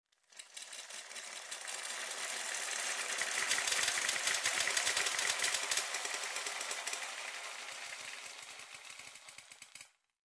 器乐层 " 170903I
描述：高音域的乐团样本混合
声道立体声